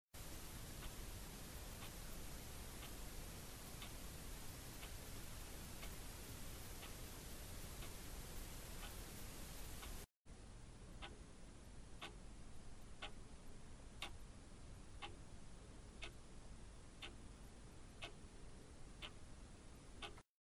This is a recording made with an Roland/Edirol R-09 recorder and a Sony ECM-717 microphone. The first 10 seconds are the mic straight into the recorder. The rest of the recording is with a 3.5 Series preamp inserted into the circuit. The level of the second part is reduced by 20 dB to equalise the programme material level.